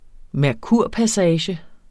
Udtale [ mæɐ̯ˈkuɐ̯ˀ- ]